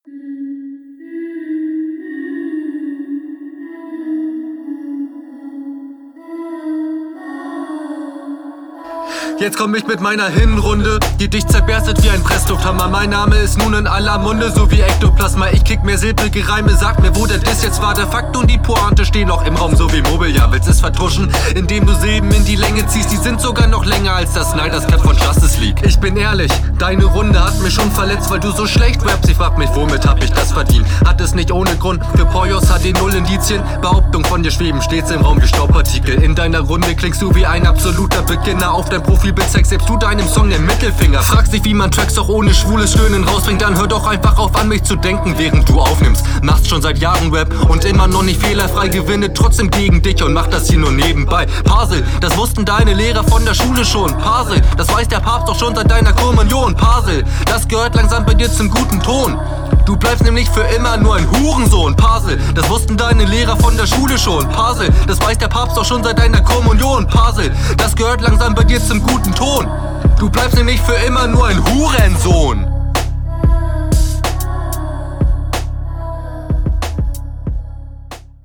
Audioqualität direkt viel schwächer als in der RR, schade.
Epischer Beat, okay.